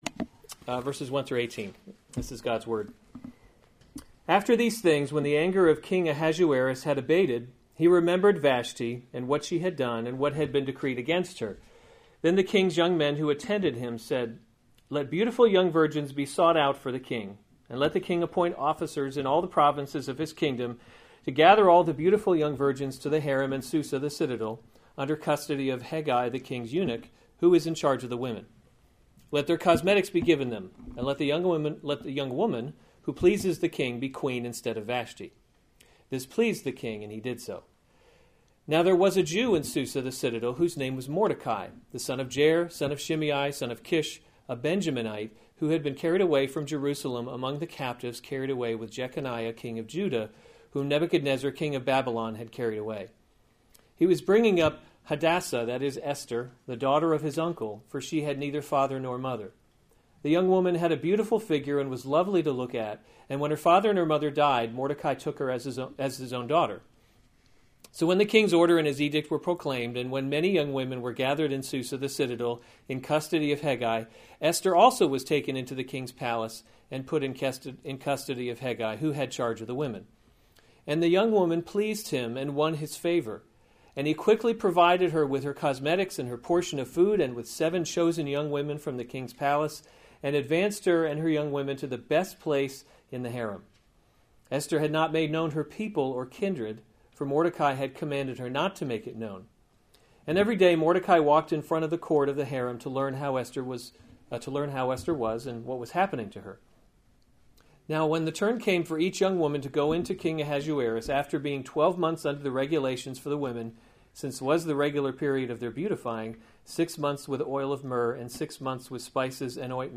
September 24, 2016 Esther: God’s Invisible Hand series Weekly Sunday Service Save/Download this sermon Esther 2:1-18 Other sermons from Esther Esther Chosen Queen 2:1 After these things, when the anger of […]